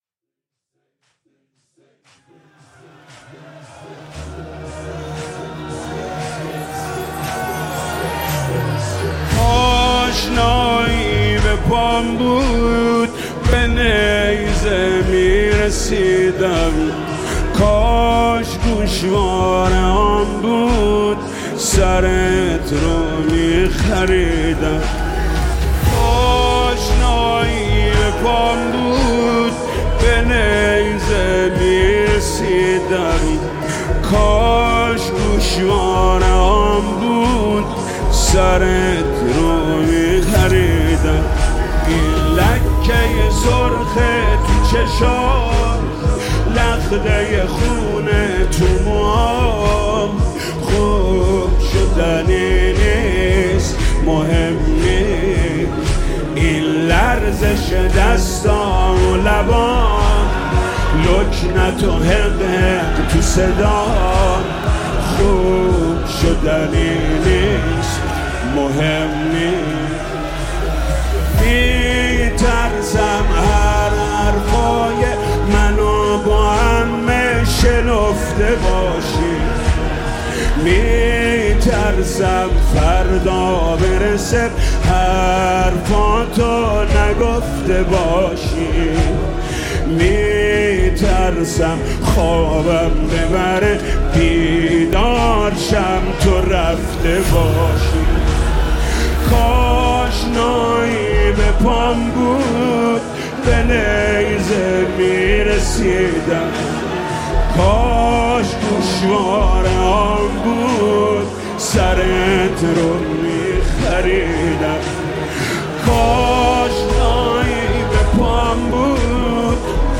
نوای دلنشین